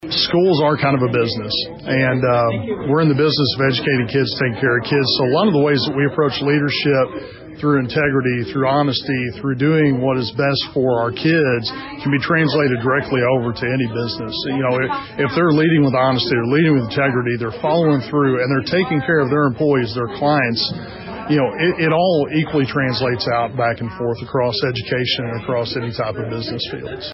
Desloge, Mo. (KFMO) - Community members and business leaders gathered for the October luncheon of the Desloge Chamber of Commerce.